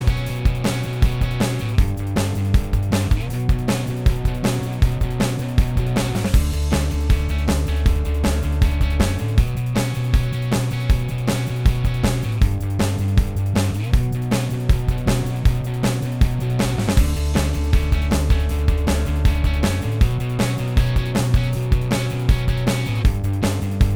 Minus All Guitars Rock 4:06 Buy £1.50